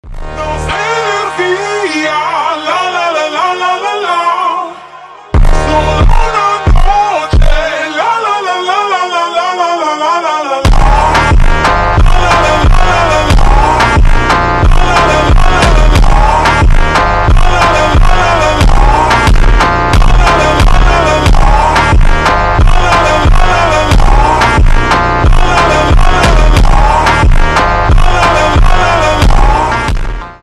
басы , бразильский фонк